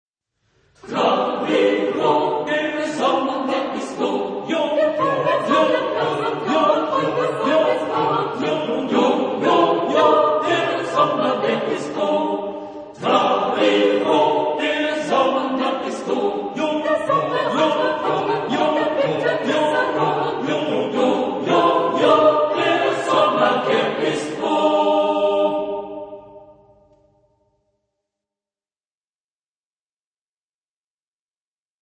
Genre-Style-Forme : Chanson ; Profane
Type de choeur : SATB  (4 voix mixtes )
Tonalité : sol majeur